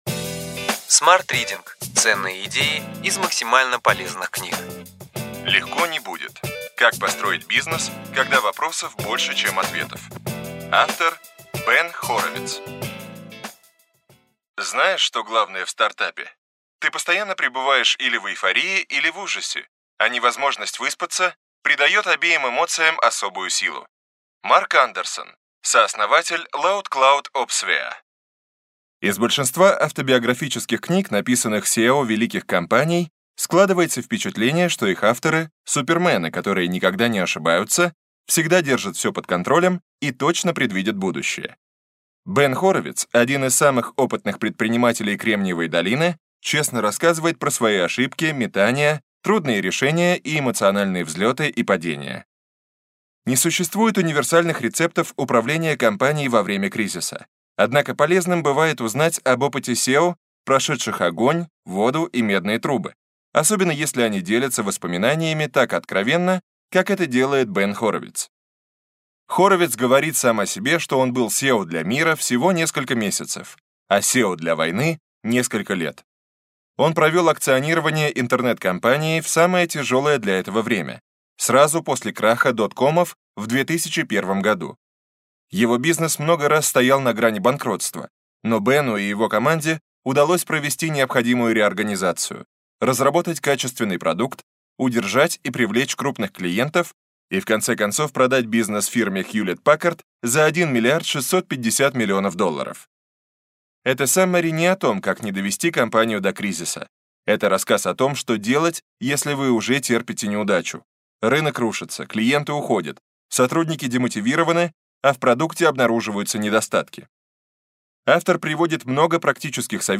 Аудиокнига Ключевые идеи книги: Легко не будет. Как построить бизнес, когда вопросов больше, чем ответов.